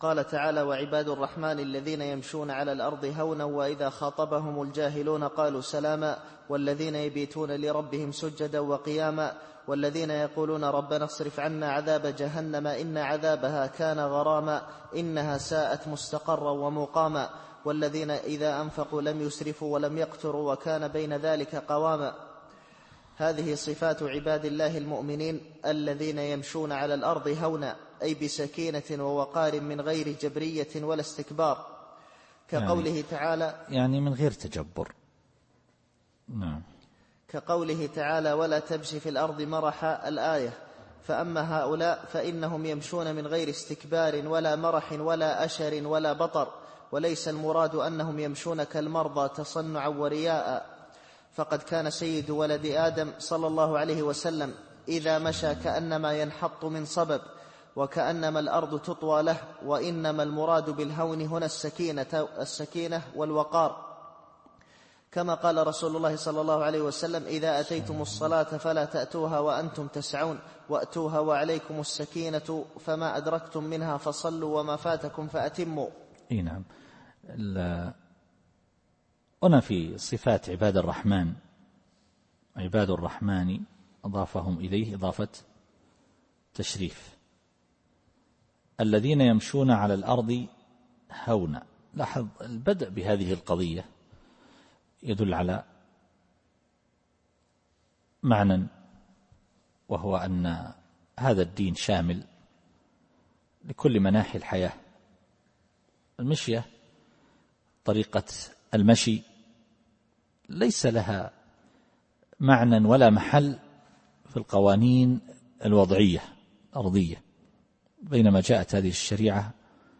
التفسير الصوتي [الفرقان / 63]